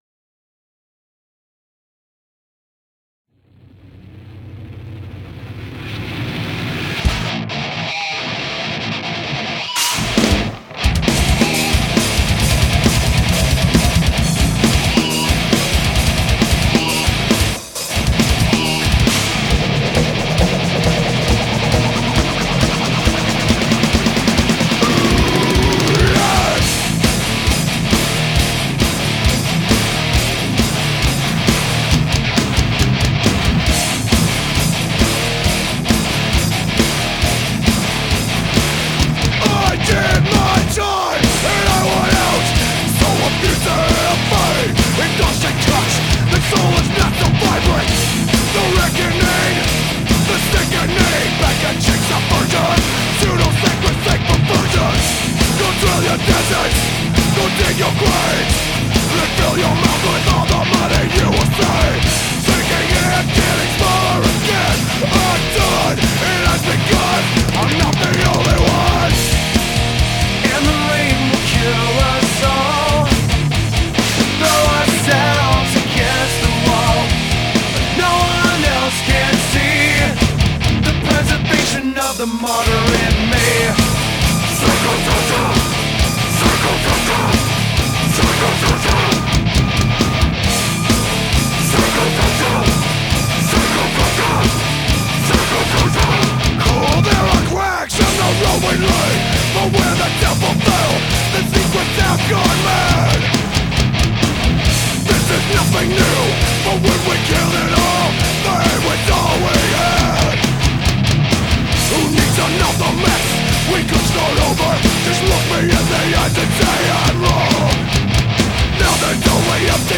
I did all of the Guitars and Bass on this recording.